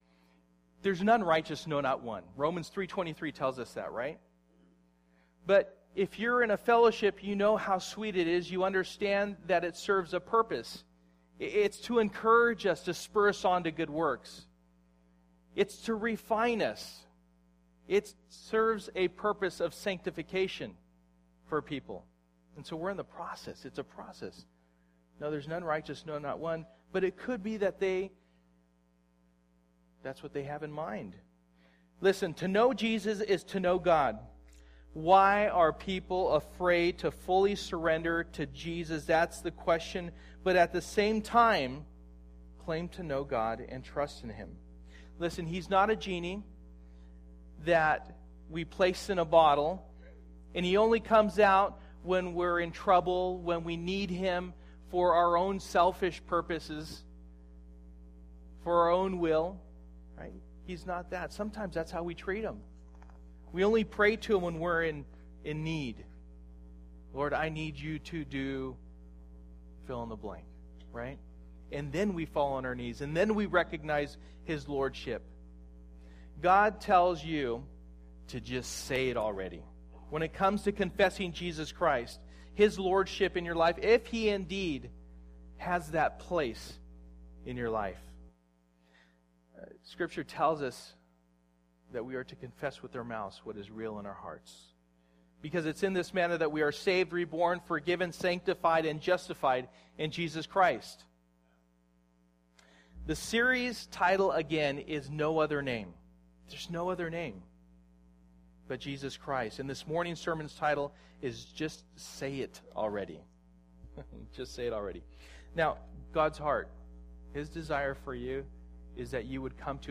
Romans 10:9 Service: Sunday Morning %todo_render% « From Brick to Living Stone No Other Name